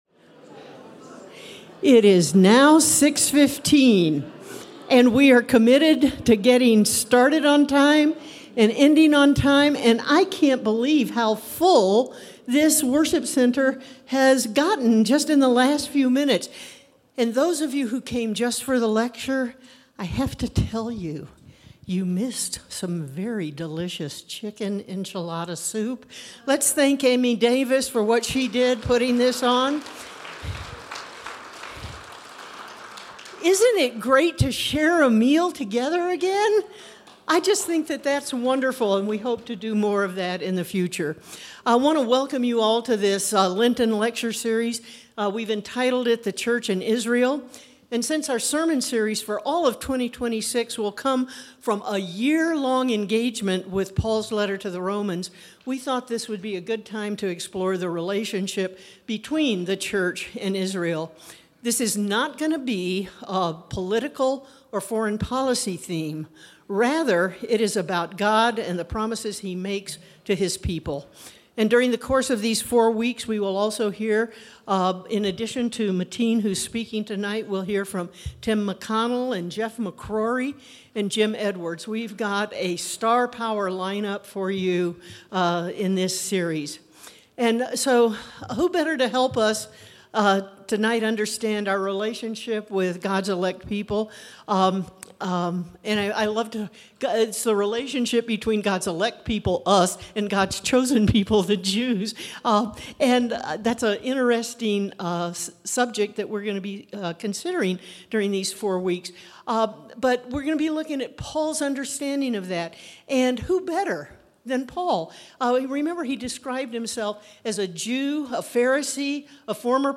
Lecture 2: November 5